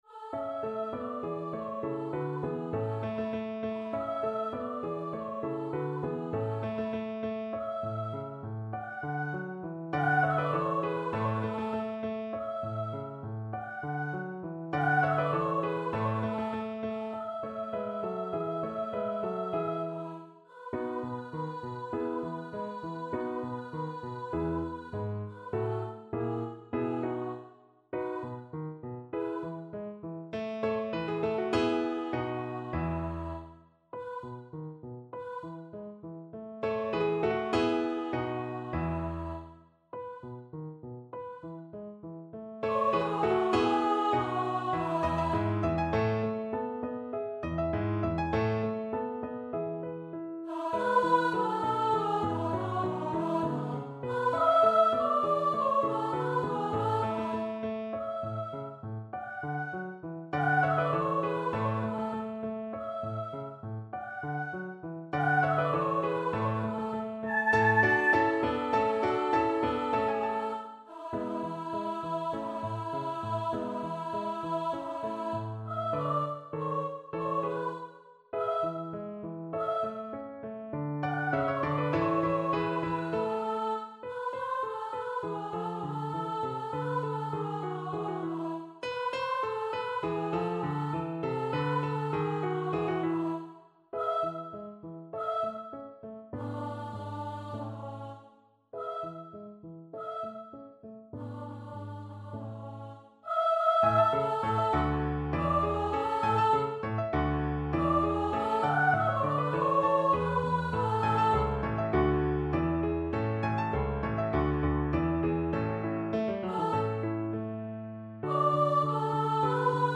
Free Sheet music for Soprano Voice
B4-A6
A major (Sounding Pitch) (View more A major Music for Soprano Voice )
2/4 (View more 2/4 Music)
Soprano Voice  (View more Intermediate Soprano Voice Music)
Classical (View more Classical Soprano Voice Music)